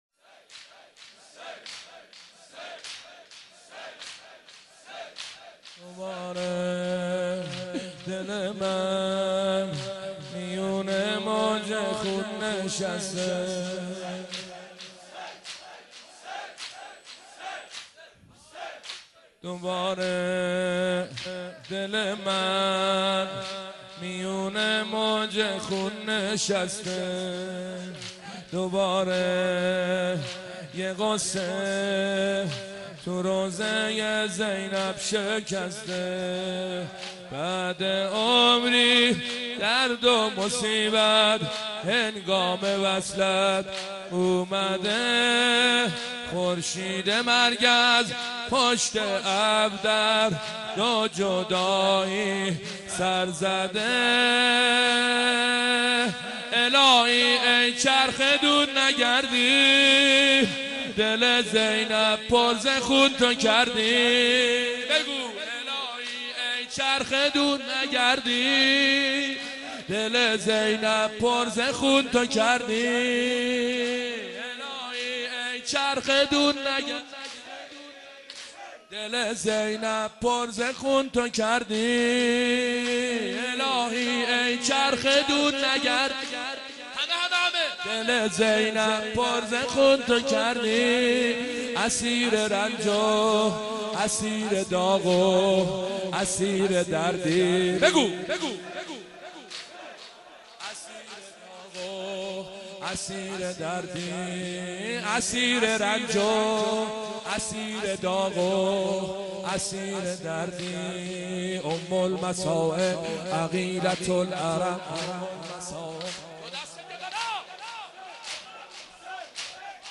مداحی
بمناسبت وفات حضرت زینب کبری سلام الله علیها